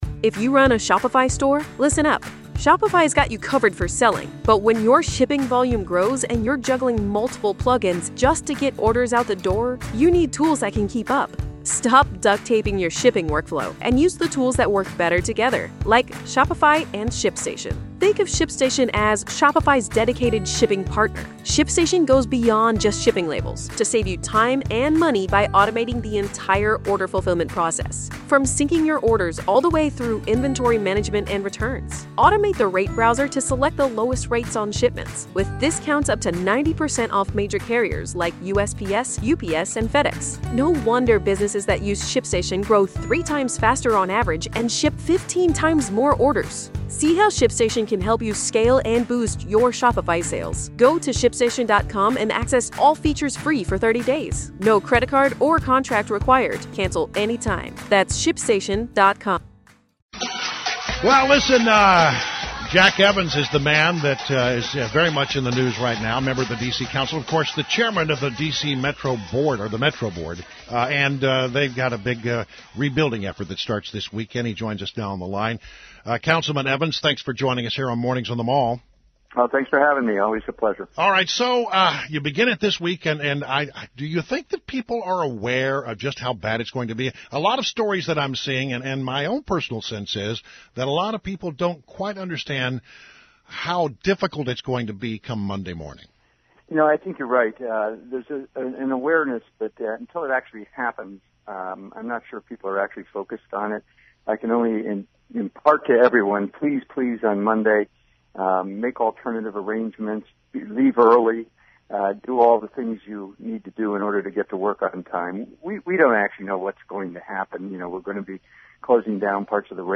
INTERVIEW – JACK EVANS – DC COUNCILMEMBER AND DC METRO BOARD CHAIRMAN